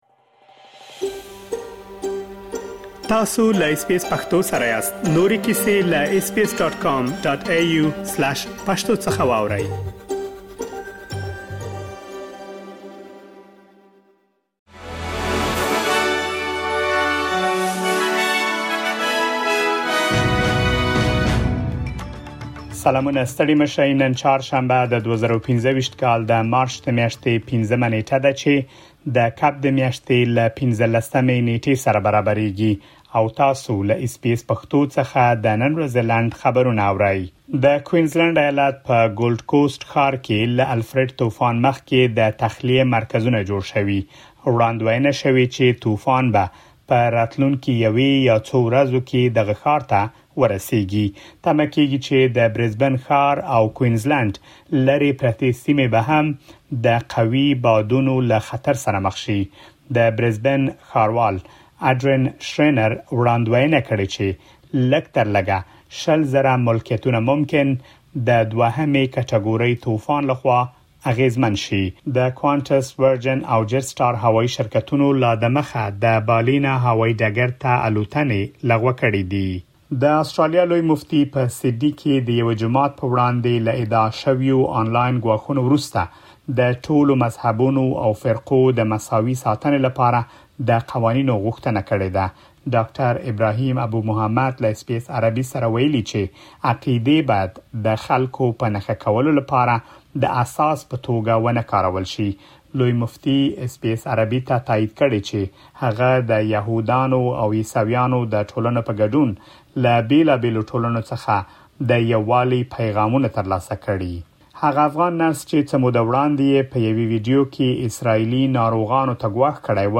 د اس بي اس پښتو د نن ورځې لنډ خبرونه | ۵ مارچ ۲۰۲۵
د اس بي اس پښتو د نن ورځې لنډ خبرونه دلته واورئ.